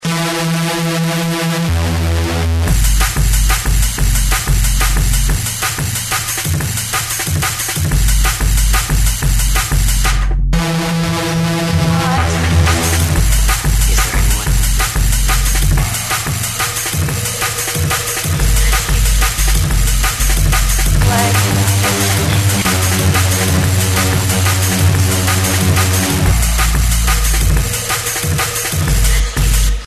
• Electronic Ringtones